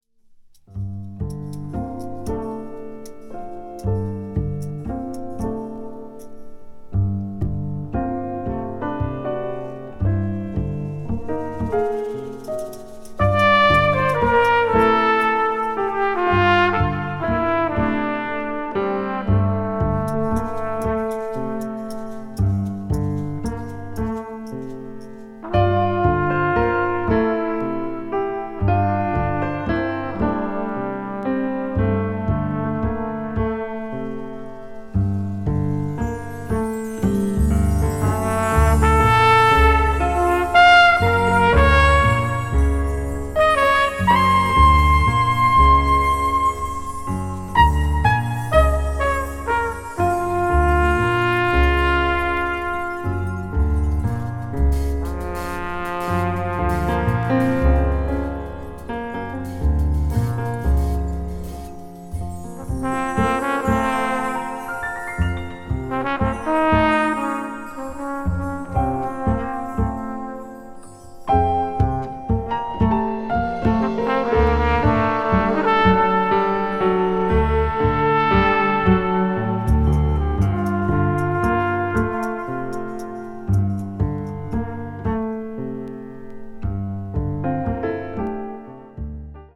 media : EX+/EX+(わずかにチリノイズが入る箇所あり)
ECM作品の大半に言える事ですが、透明度が高く、細かい粒子のように届く音に酔いしれる事が出来る素晴らしい内容です。
contemporary jazz   deep jazz   ethnic jazz   spritual jazz